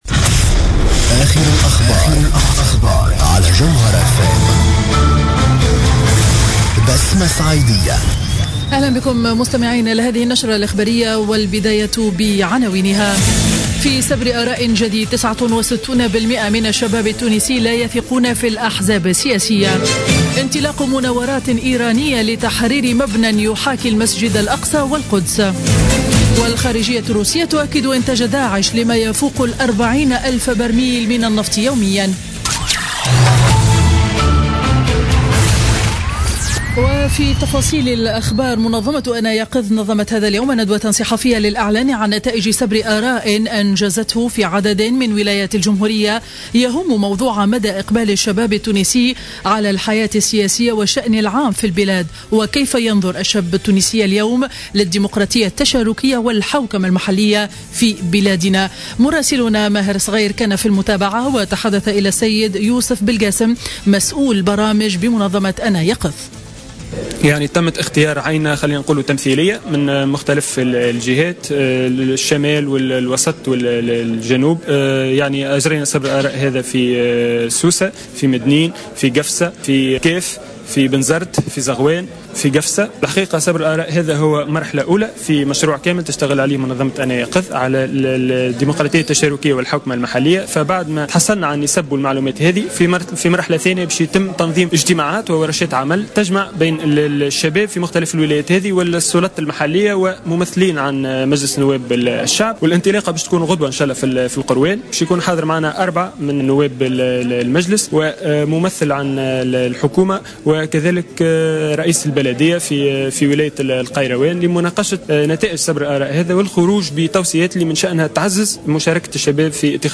نشرة أخبار منتصف النهار ليوم الجمعة 20 نوفمبر 2015